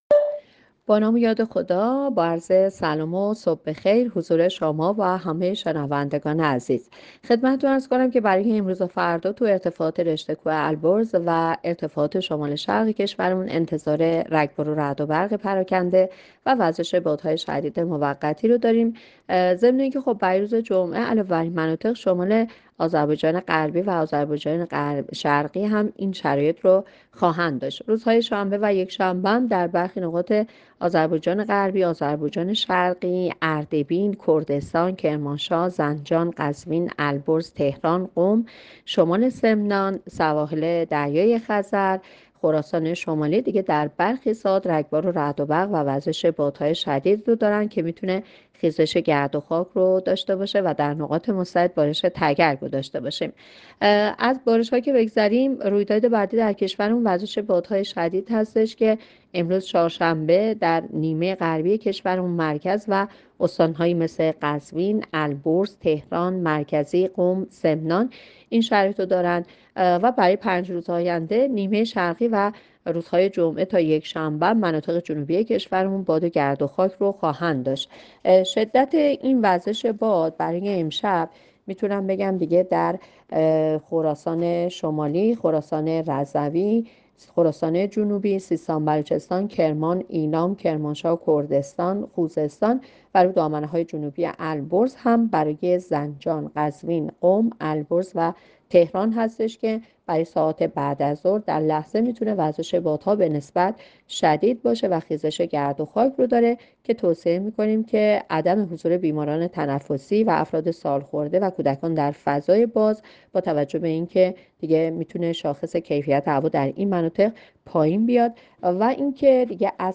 گزارش رادیو اینترنتی پایگاه‌ خبری از آخرین وضعیت آب‌وهوای ۳۱ اردیبهشت؛